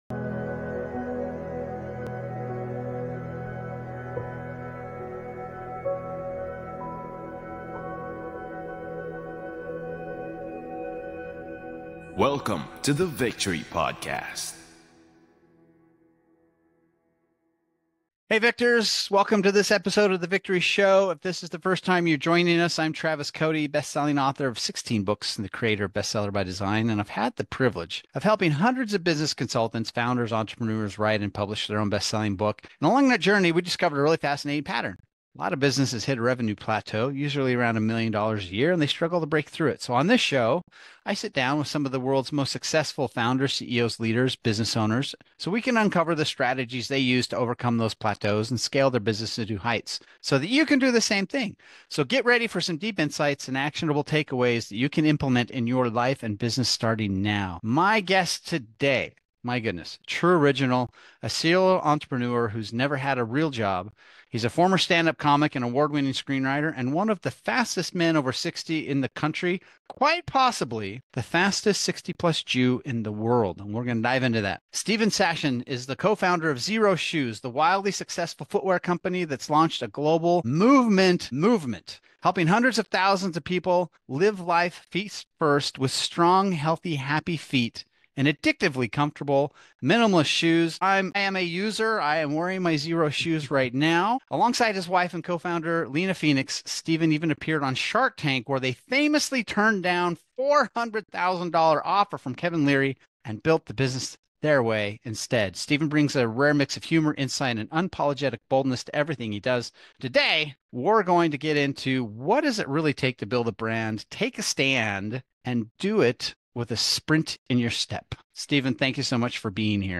A no-fluff conversation on entrepreneurship, resilience, and why everything in business is marketing.